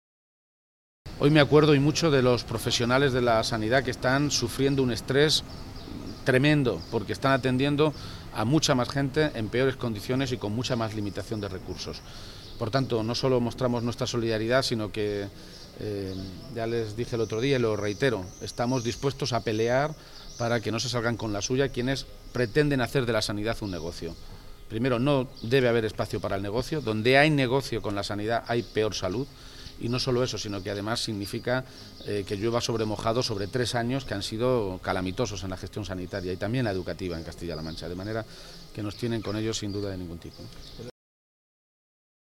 García-Page se pronunciaba de esta manera esta mañana, en Toledo, a preguntas de los medios de comunicación sobre la huelga convocada en todos los hospitales del complejo sanitario público de la ciudad de Toledo y añadía que “desde el PSOE vamos a pelear para que no se salgan con la suya (en referencia al Gobierno de Cospedal), los que quieren hacer de la Sanidad pública un negocio”.
Cortes de audio de la rueda de prensa